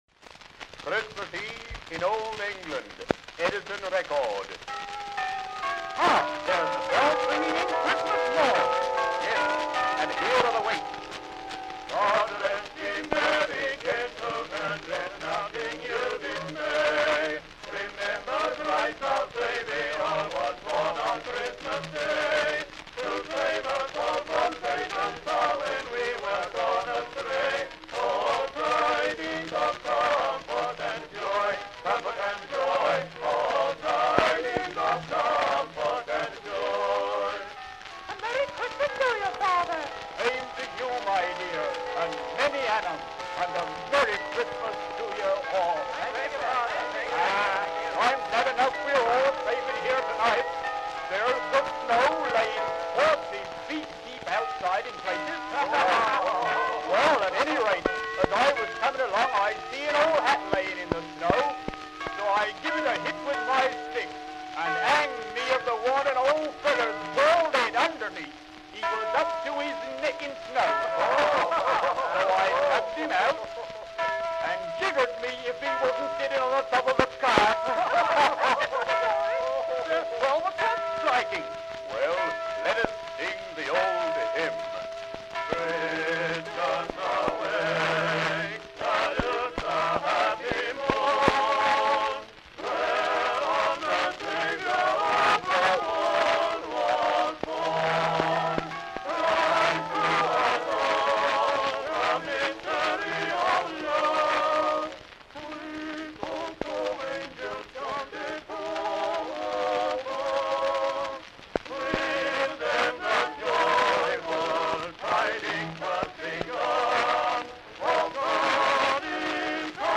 Christmas carols.
Popular music—1901-1910.
Carols, English.
Recitations.